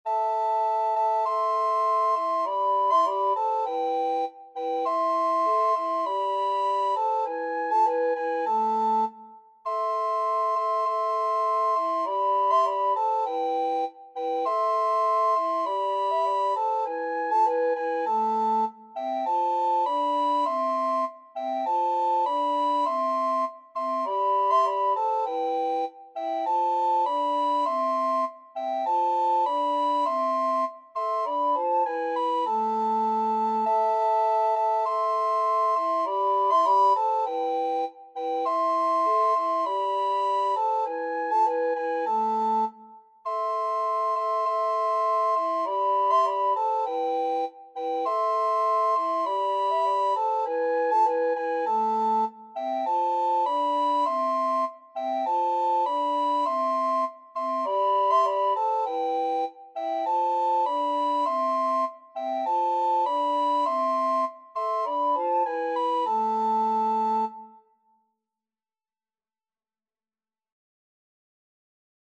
Free Sheet music for Recorder Trio
Soprano RecorderAlto RecorderBass Recorder
C major (Sounding Pitch) (View more C major Music for Recorder Trio )
4/4 (View more 4/4 Music)
Recorder Trio  (View more Easy Recorder Trio Music)
Classical (View more Classical Recorder Trio Music)